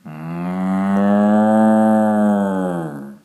cow-moo-2.mp3